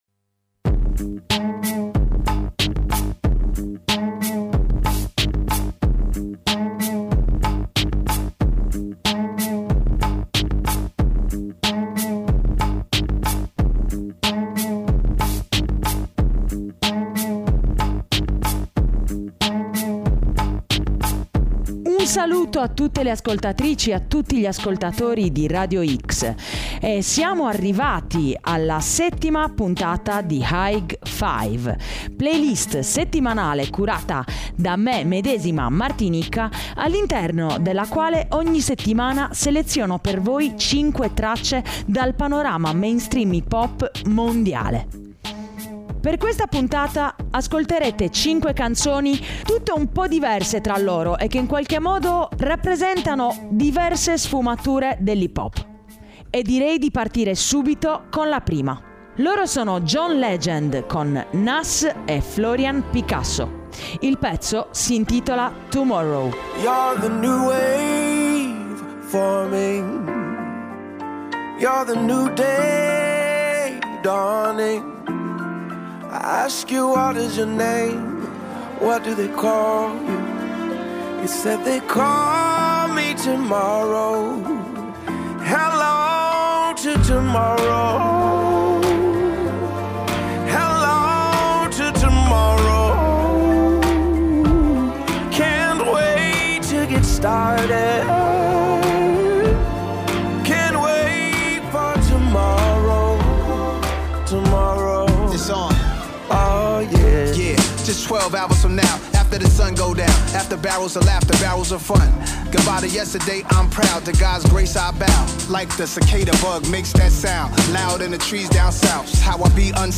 hip hop / rap / trap